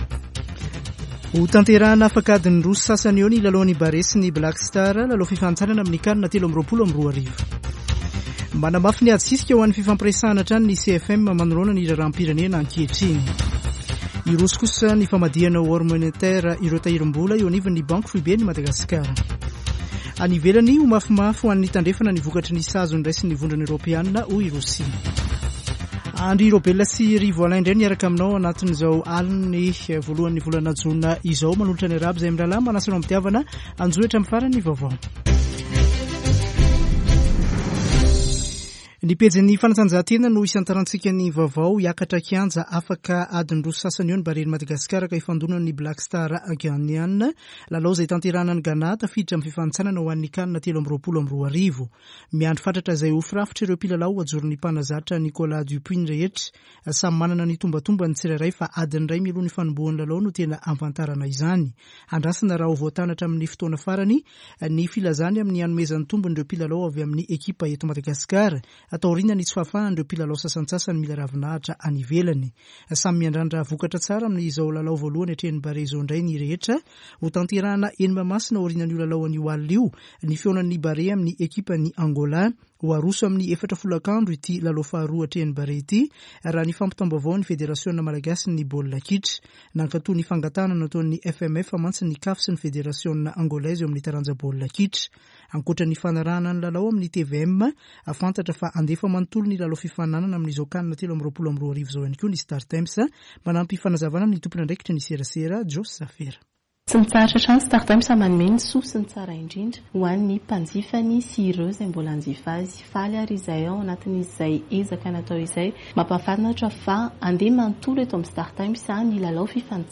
[Vaovao hariva] Alarobia 1 jona 2022